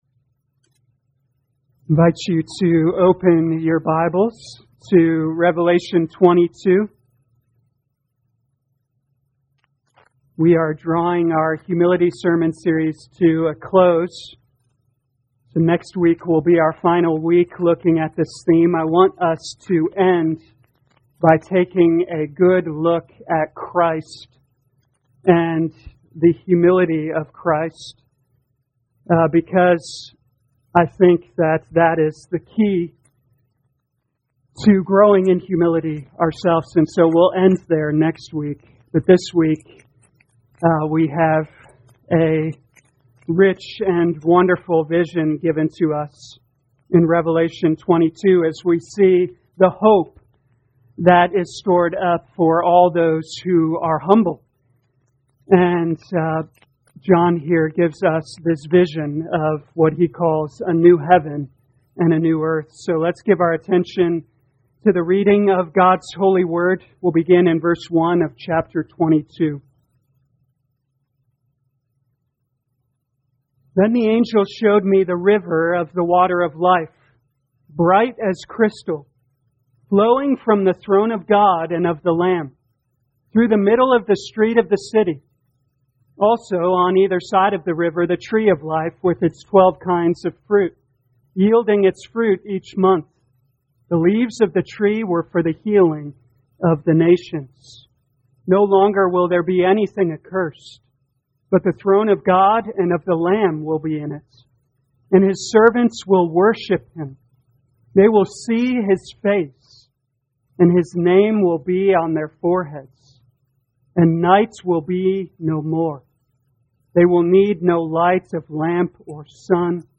2021 Revelation Humility Morning Service Download